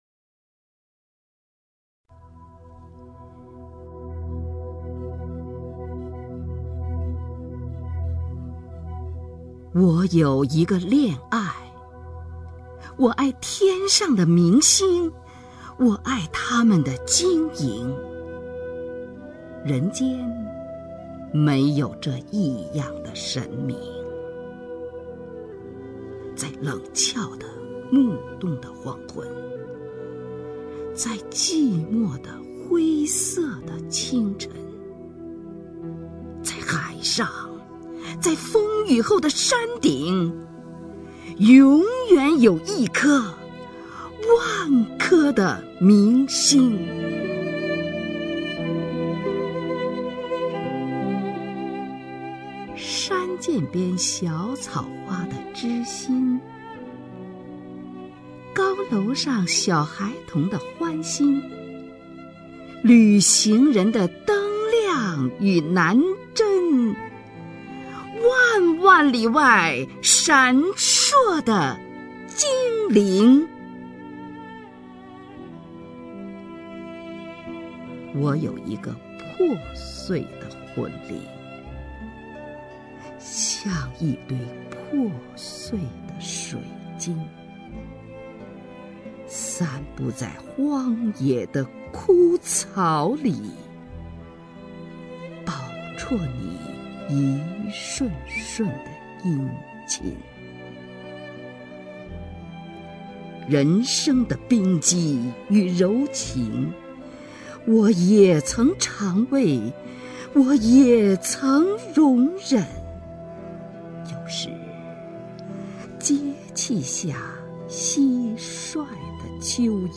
首页 视听 名家朗诵欣赏 虹云
虹云朗诵：《我有一个恋爱》(徐志摩)　/ 徐志摩